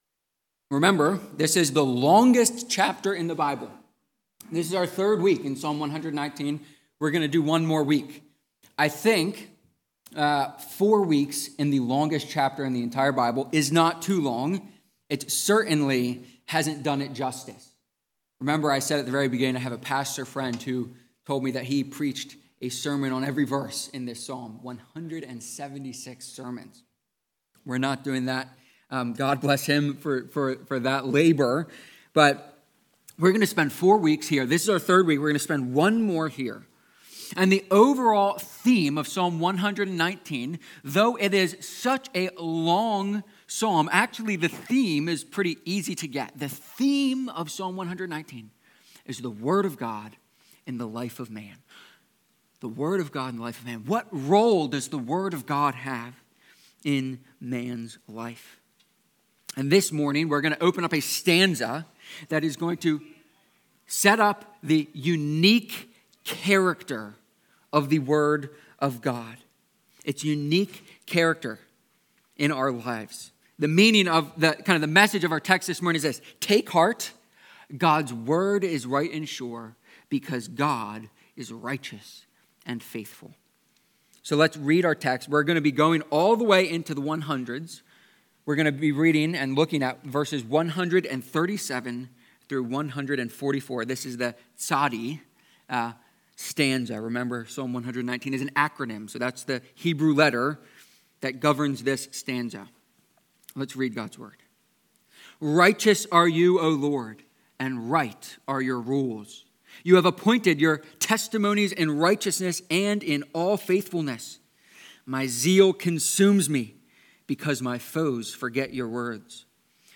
Psalm-119-sermon-3.mp3